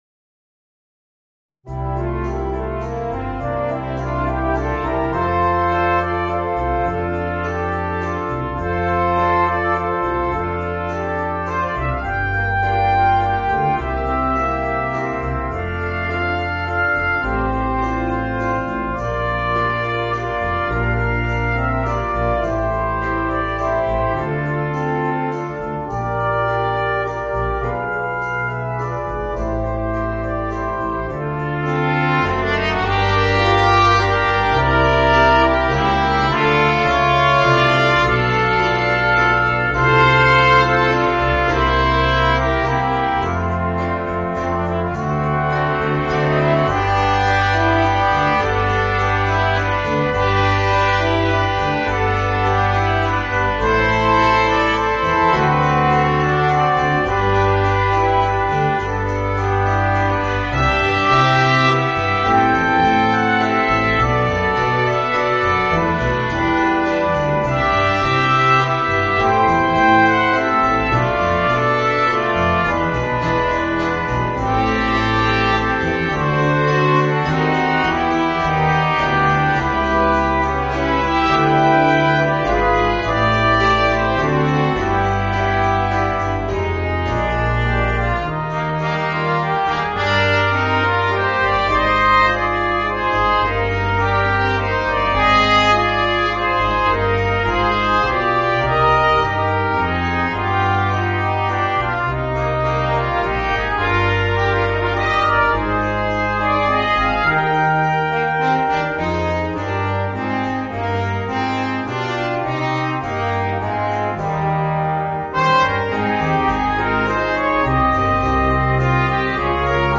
Voicing: 3 Trumpets and Trombone